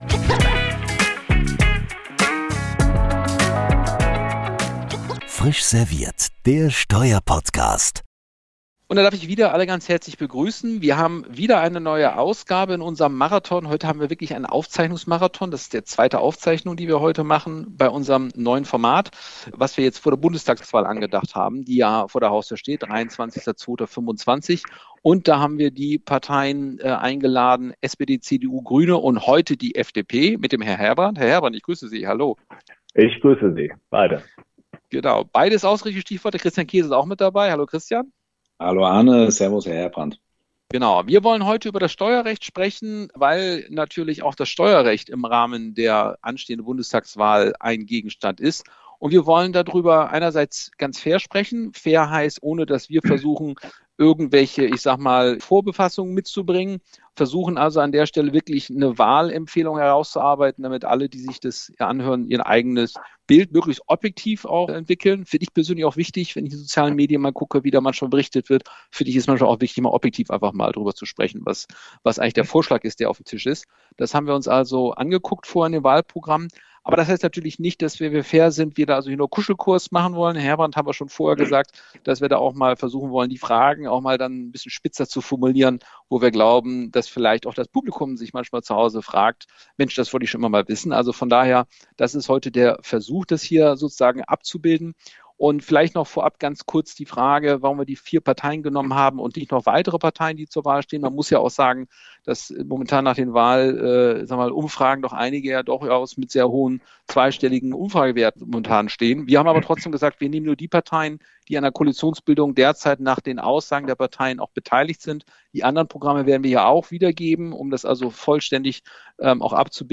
In dieser Folge ist Markus Herbrand, finanzpolitischer Sprecher und Obmann im Finanzausschuss für die FDP-Bundestagsfraktion, zu Gast.